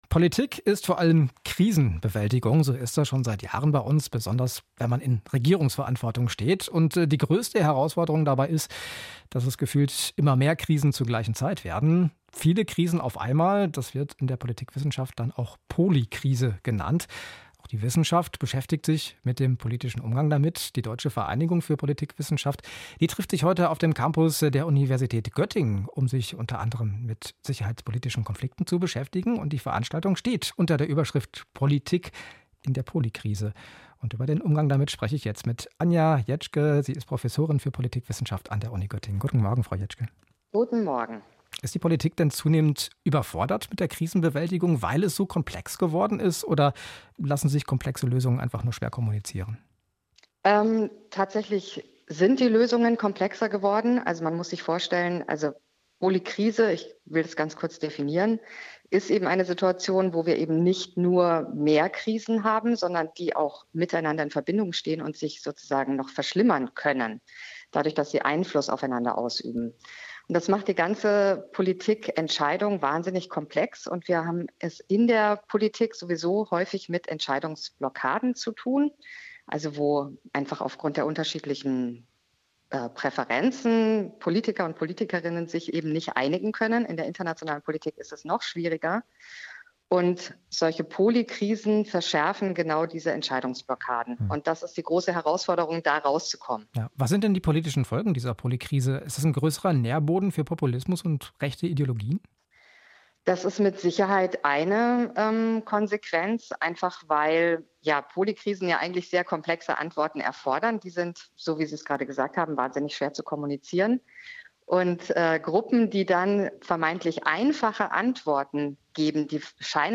Interview - Politikwissenschaft - Internationale Beziehungen - Georg-August-Universität
Interview zu Politik in der Polykrise